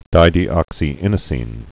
(dīdē-ŏksē-ĭnə-sēn, -sĭn, -īnə-)